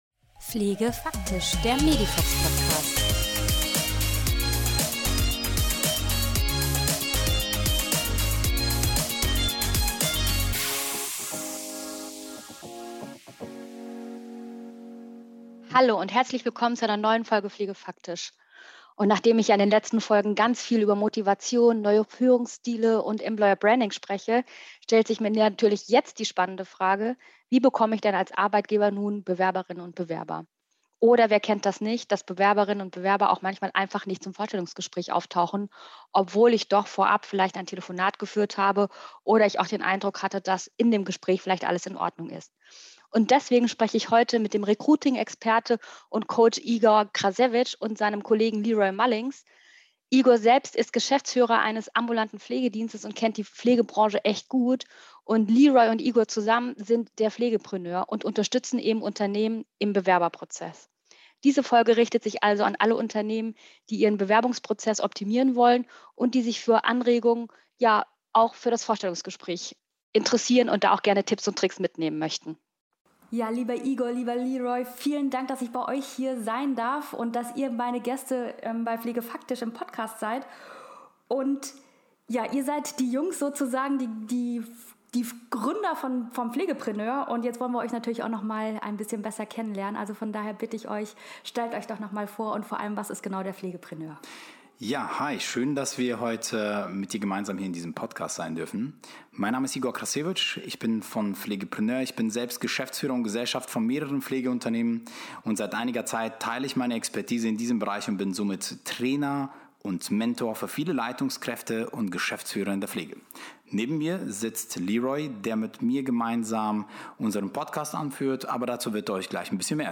Sie sind Recruiting Experten und können auf langjährige Erfahrung zurückgreifen.
Die Experten verraten den ein oder anderen Tipp für den Bewerbungsprozess und sind ansonsten auch witzige Gesprächspartner.